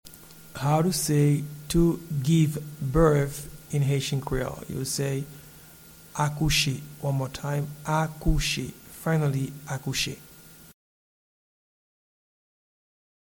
Pronunciation and Transcript:
Give-birth-in-Haitian-Creole-Akouche.mp3